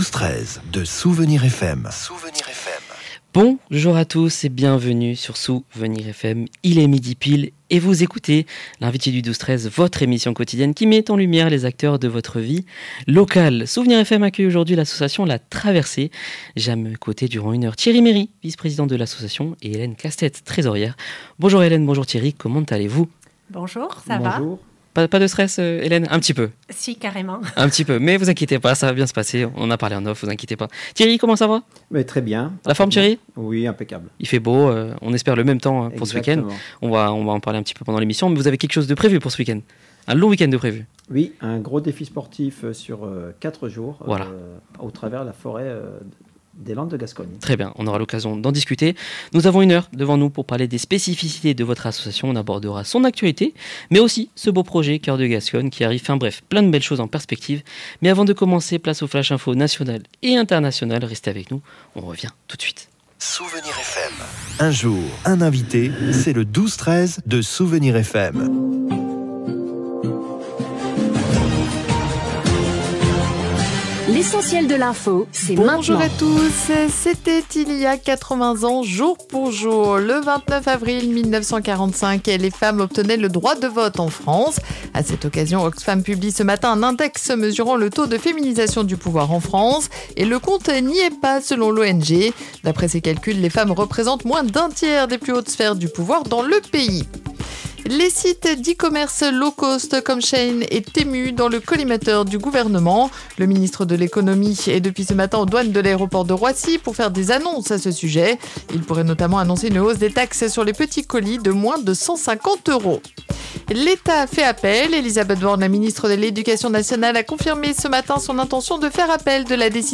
Au micro, ils ont partagé l’esprit de cette aventure, qui ne se résume pas à une performance sportive.